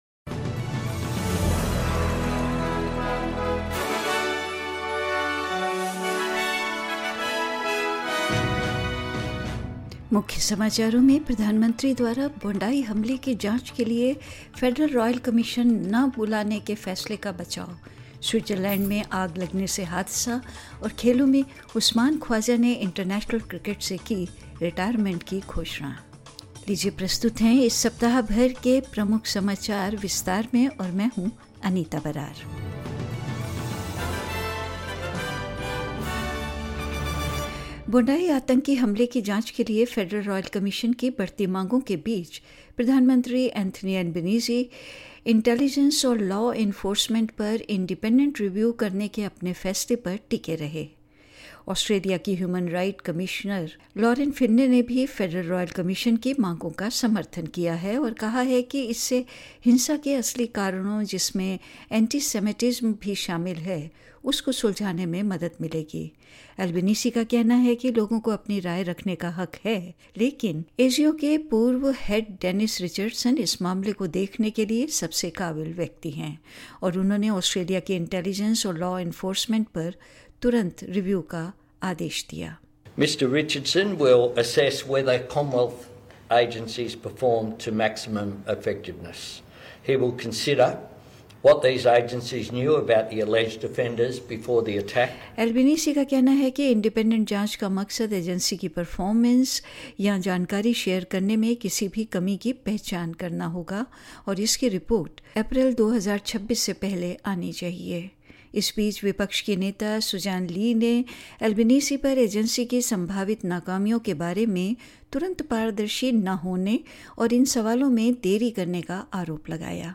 Listen to the Weekly News Wrap of the week ending Friday, 02/01/2026